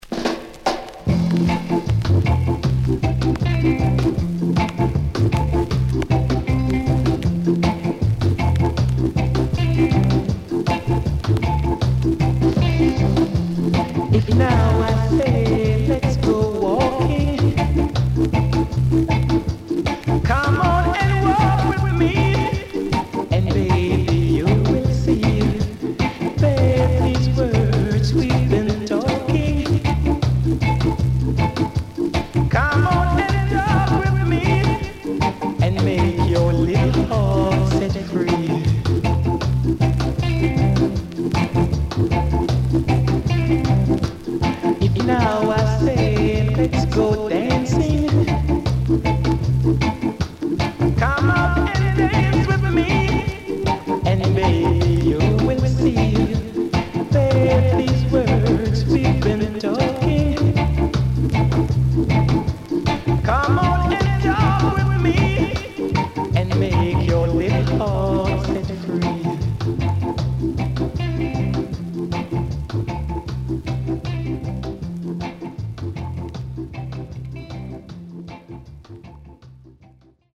SIDE A:プレス起因でノイズ入ります。少しプチノイズ入ります。